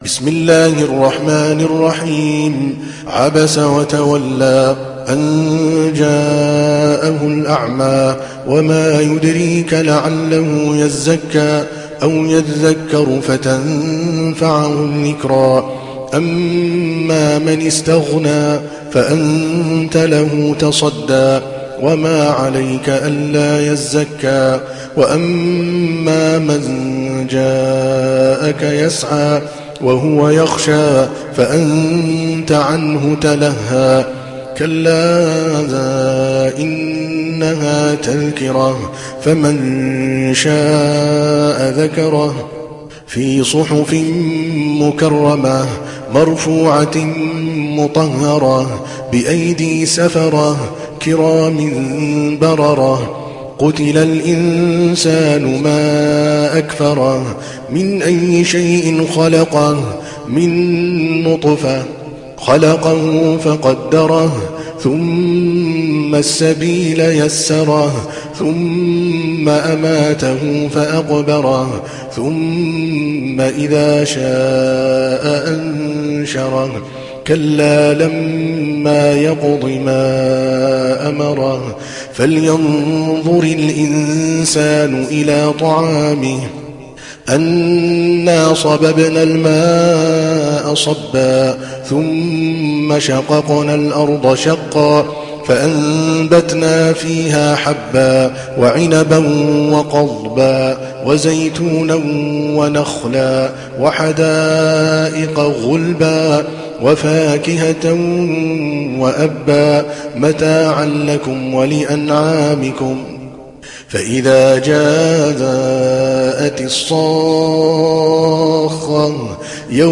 دانلود سوره عبس mp3 عادل الكلباني (روایت حفص)